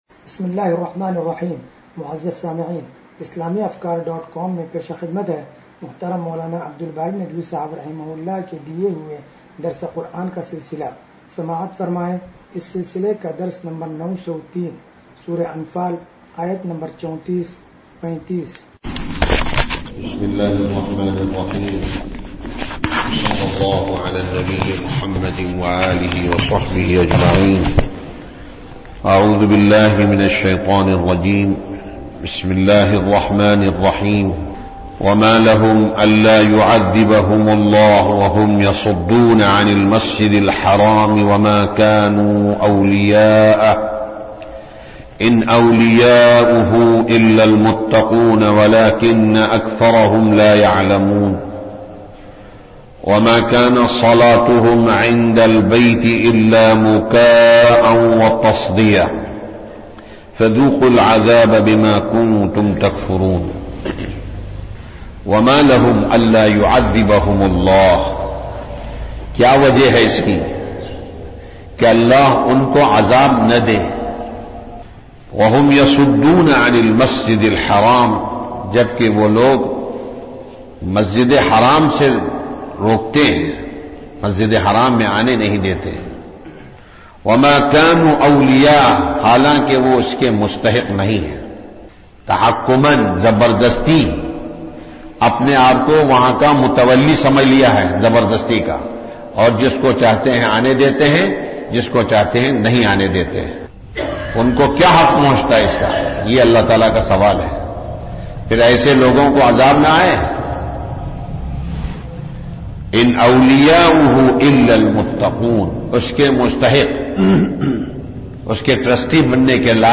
درس قرآن نمبر 0903